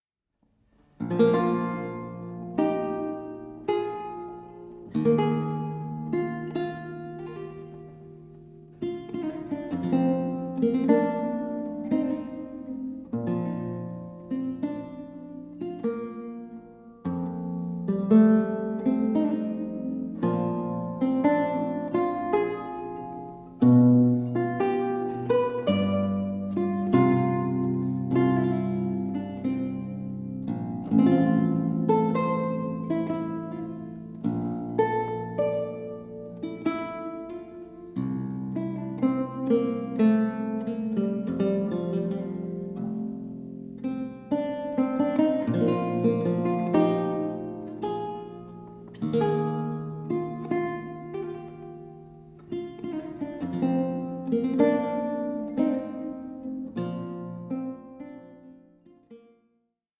mandolin